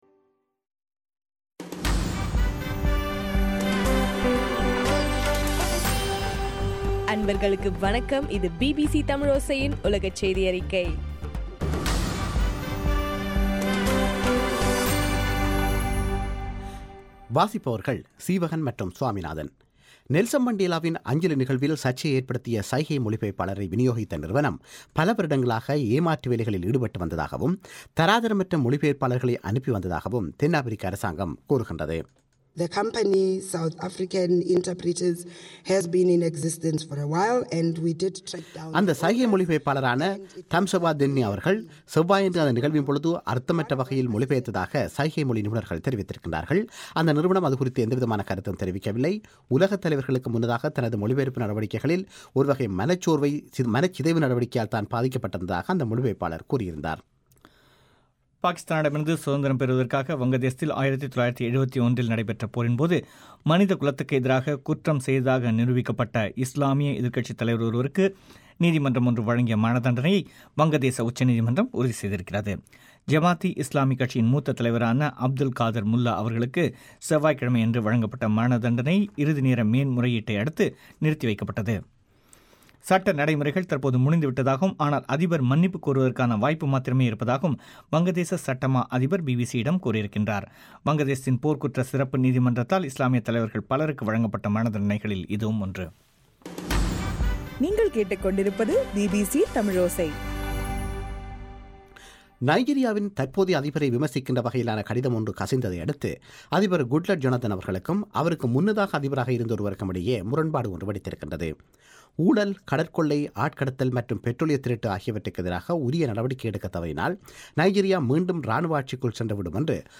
டிசம்பர் 12 பிபிசியின் உலகச் செய்திகள்